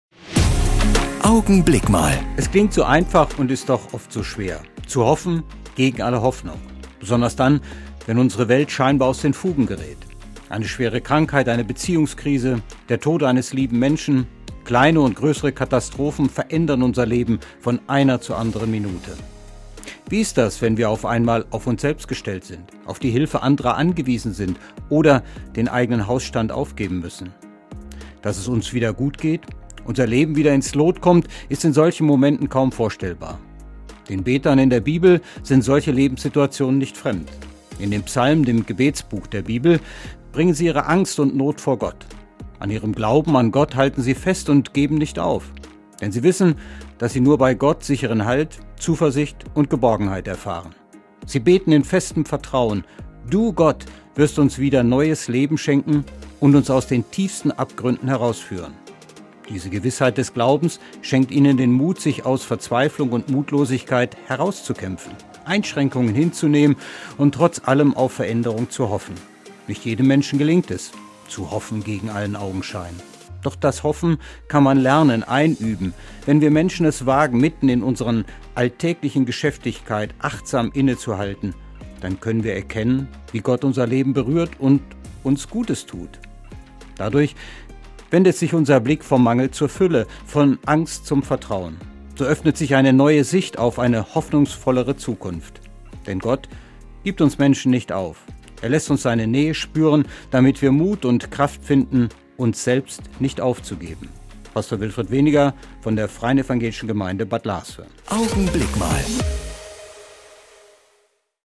Augenblick Mal - Die Kurzandacht im Radio
Jeden Sonntag gegen halb neun bei Radio Siegen zu hören: Die Kurzandacht der Kirchen (evangelisch und katholisch) - jetzt auch hier im Studioblog zum Nachhören.